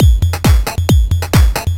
DS 135-BPM C5.wav